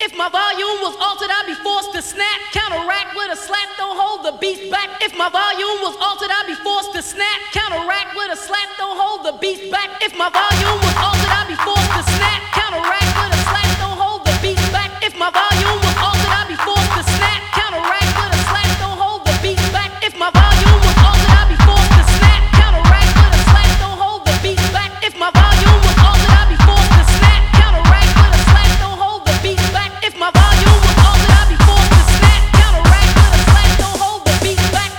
Жанр: Пост-хардкор / Хард-рок